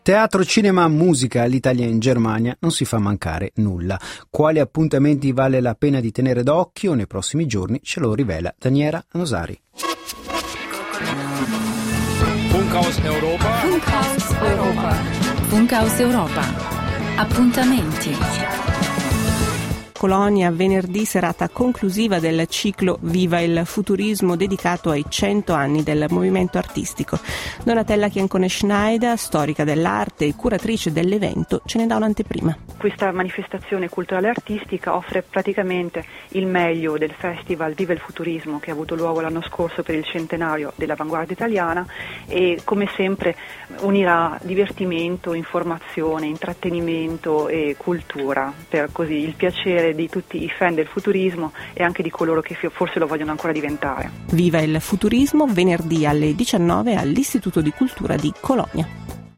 AUDIO: RADIOBEITRÄGE